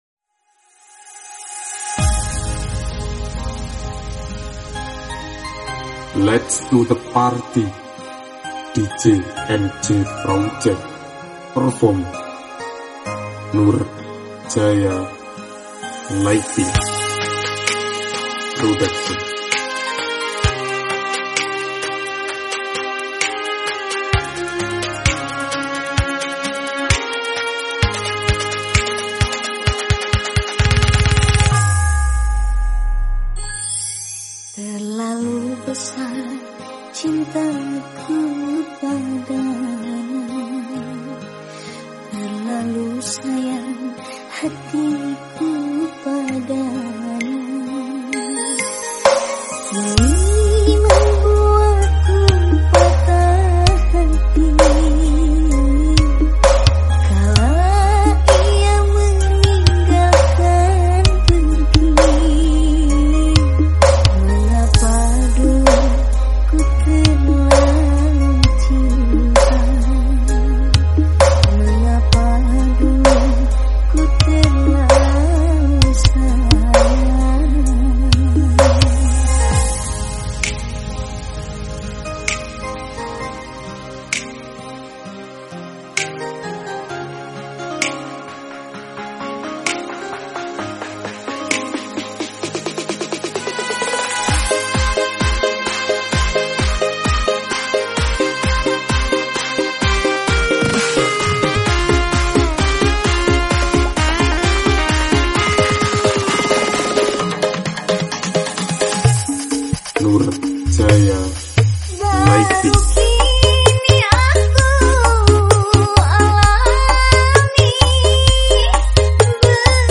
dangdut full nrotok bass